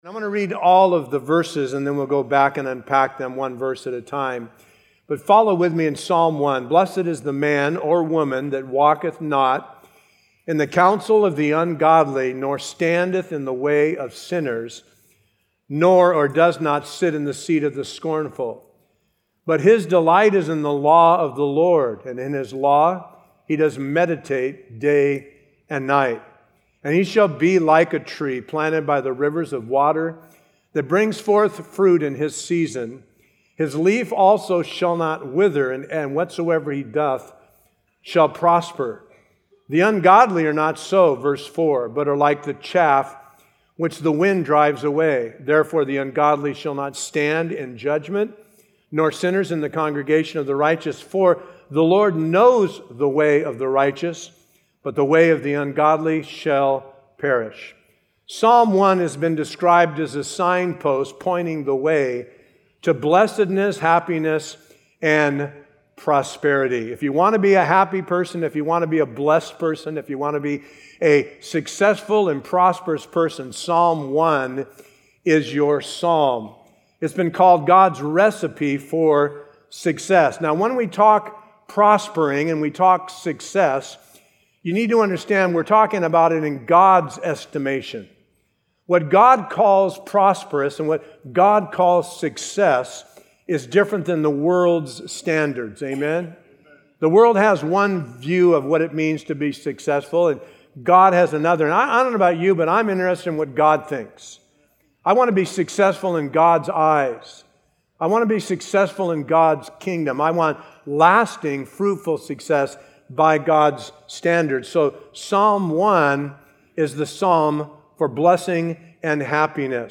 A verse-by-verse expository sermon through Psalms 1